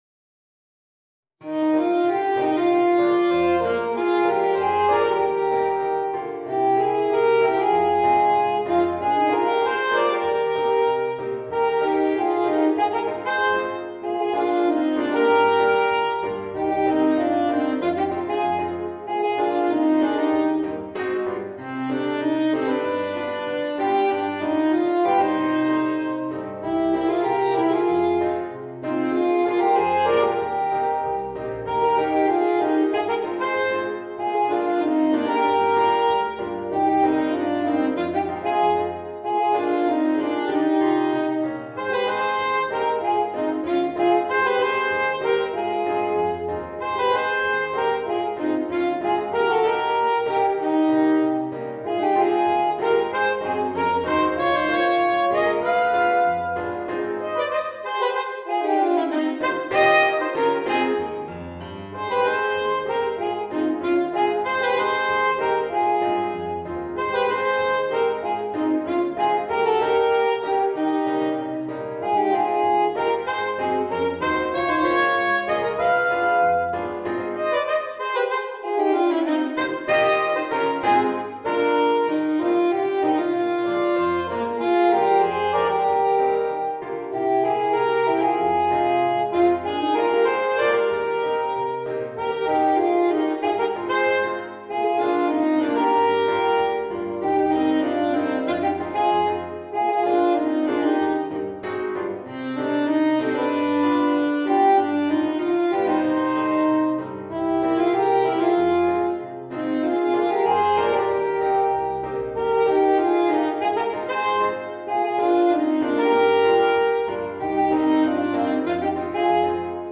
Voicing: 2 Alto Saxophones and Piano